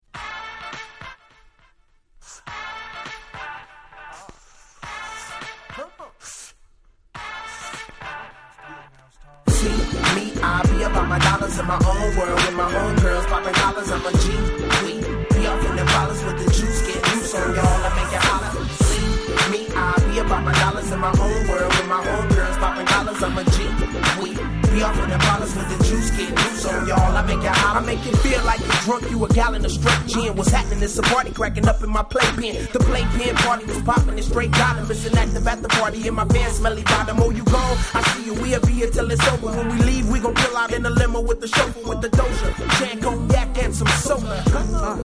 01' West Coast Hip Hop Classic !!